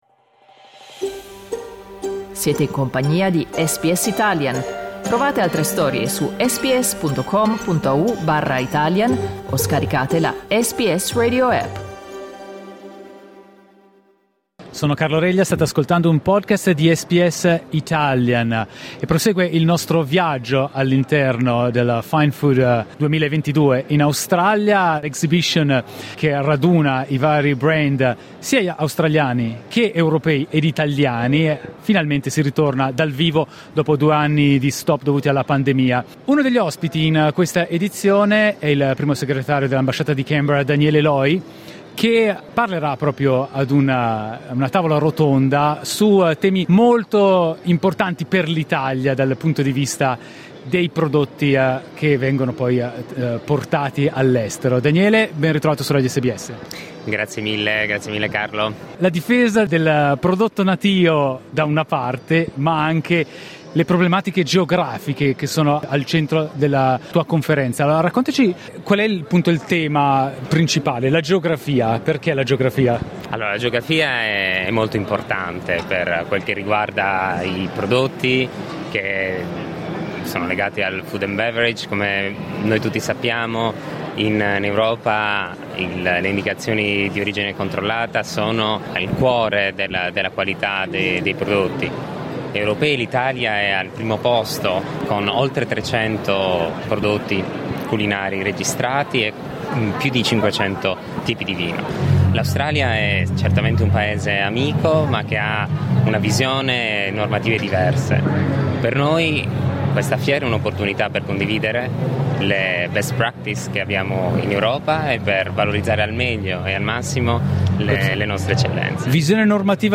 L'occasione è stata Fine Food Australia, la fiera del settore agroalimentare in programma a Melbourne dal 5 all'8 settembre che torna dopo due anni di pausa, dovuti alle restrizioni per combattere la pandemia del COVID-19.